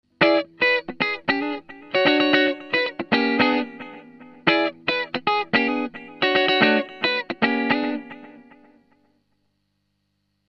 Аналоговый дилэй Electro-Harmonix Deluxe Memory Man
Funky Rhythm (122 кБ)
funky_rhythm.mp3